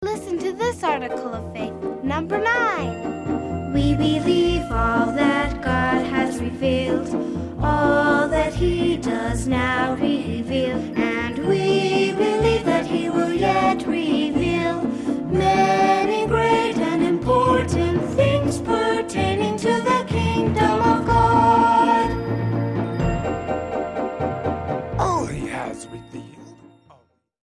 Selected Song Samples